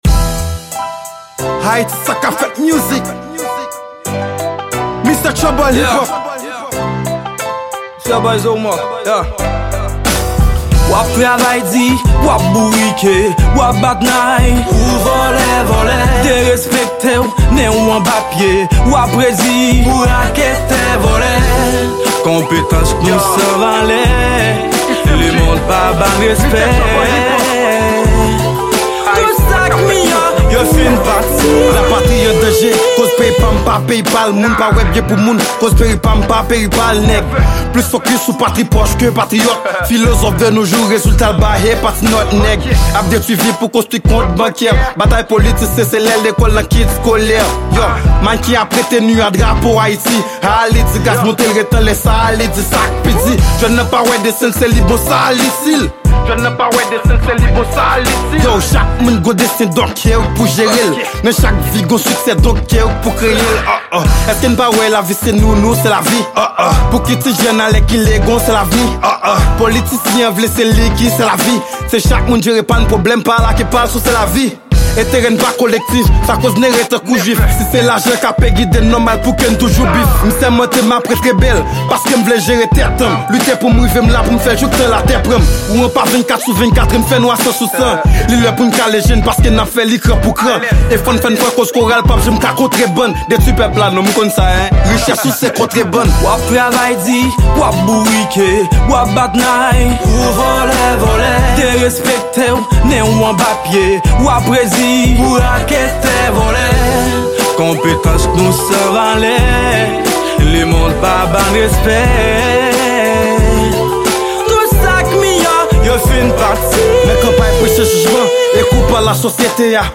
Genre&: Rap